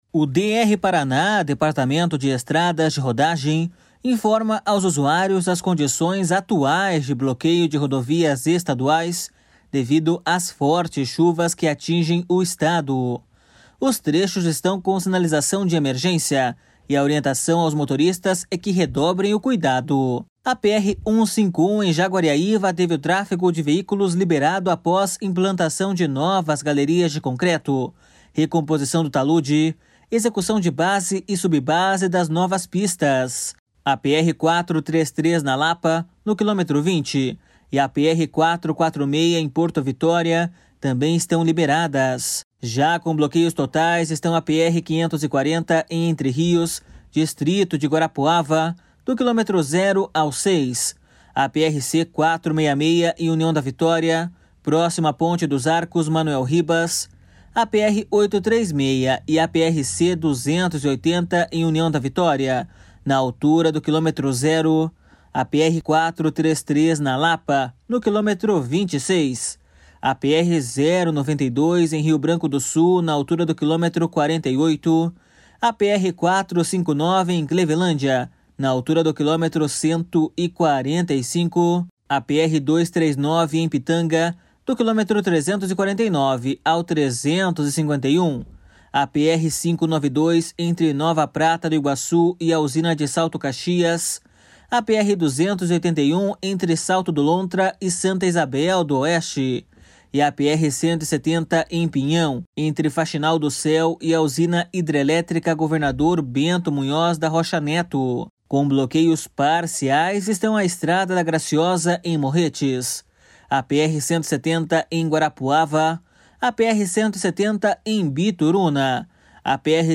BOLETIM RODOVIAS ESTADUAIS 04-11.mp3